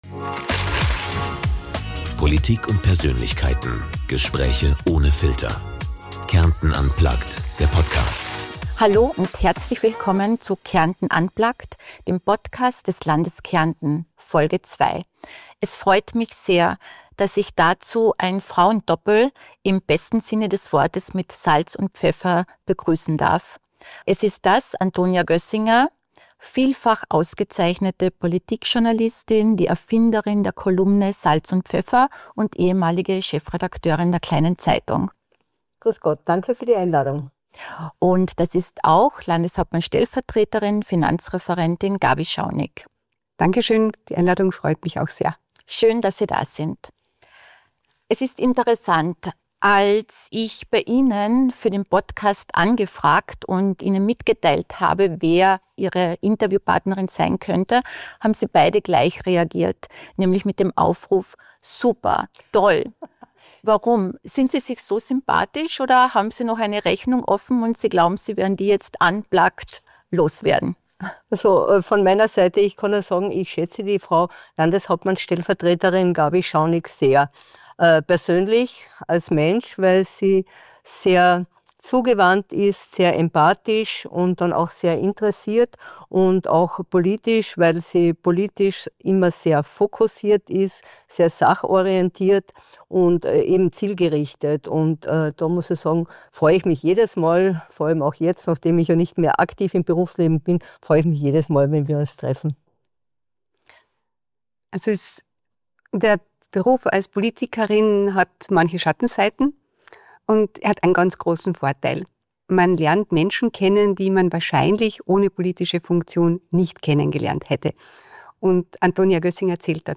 Ein Frauen-Doppel mit Salz und Pfeffer im Gespräch